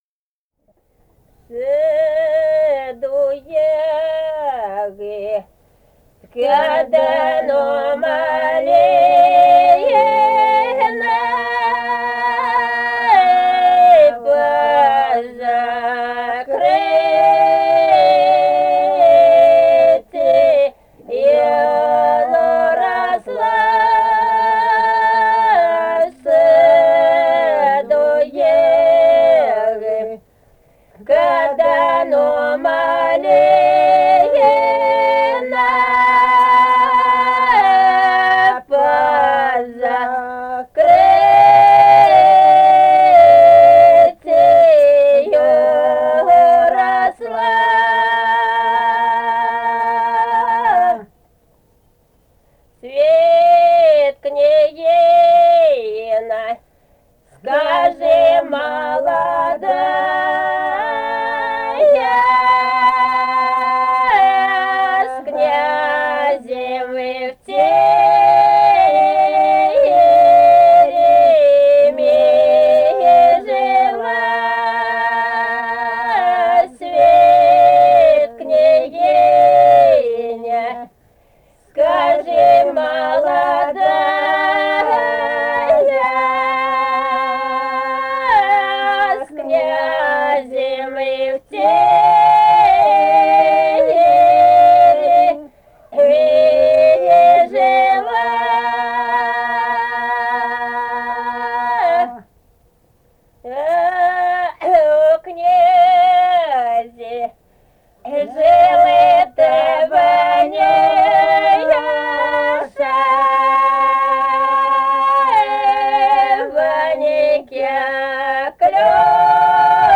Этномузыкологические исследования и полевые материалы
Самарская область, с. Усманка Борского района, 1972 г. И1316-29